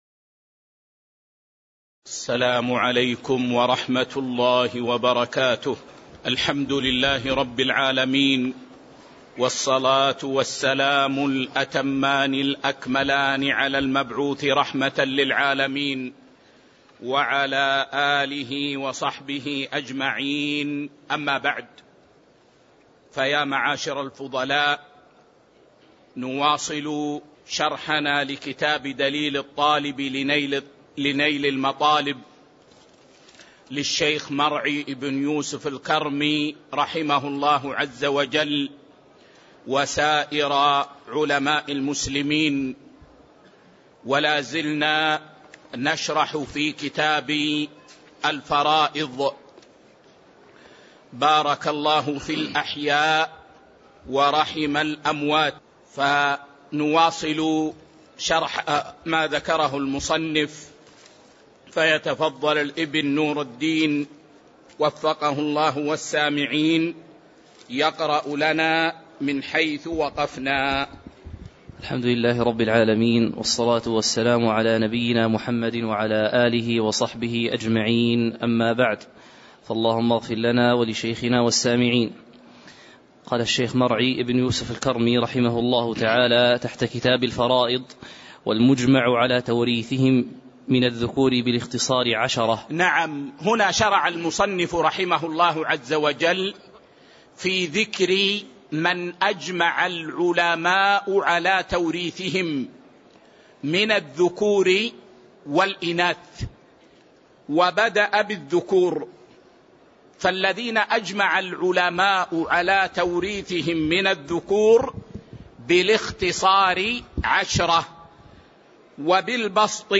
تاريخ النشر ١٦ شوال ١٤٤٤ هـ المكان: المسجد النبوي الشيخ